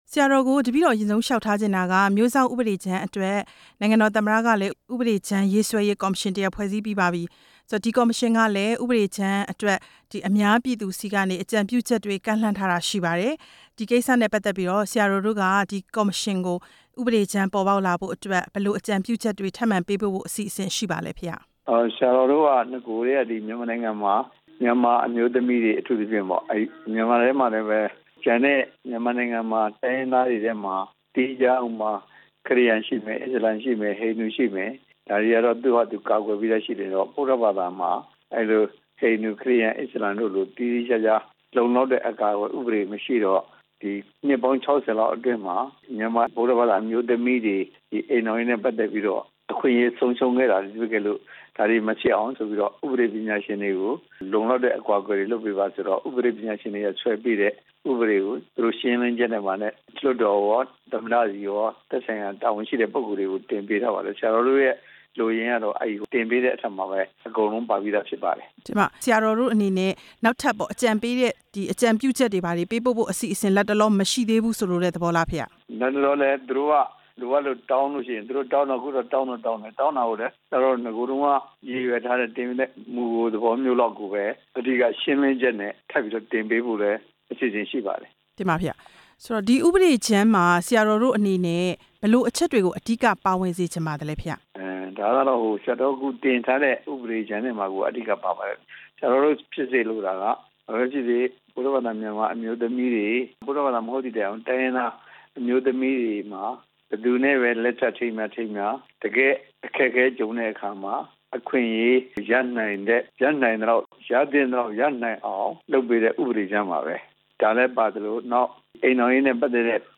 မိန့်ကြားချက်